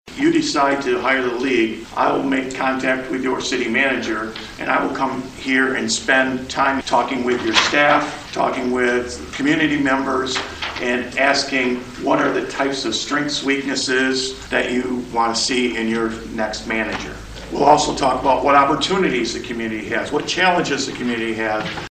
During Monday’s meeting, the Village Council heard a presentation